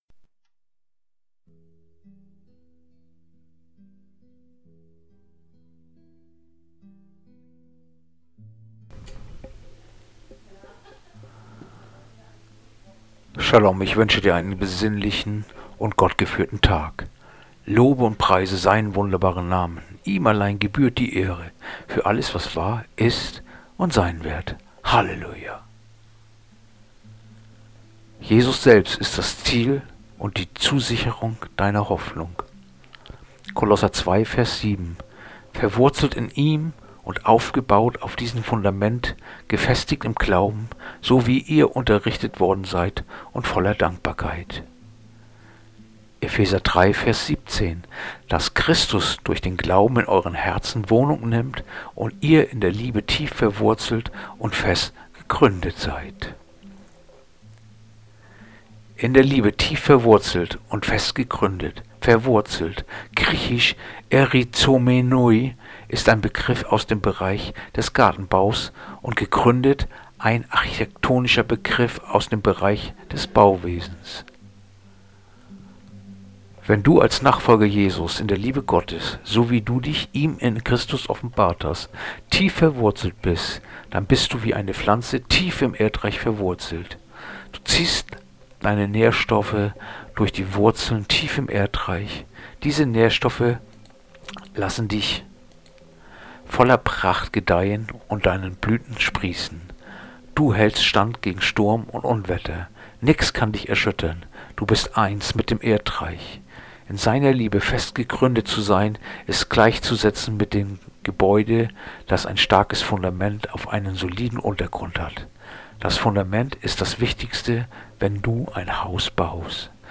Andacht-vom-02.-März-Kolosser-2-7
Andacht-vom-02.-März-Kolosser-2-7.mp3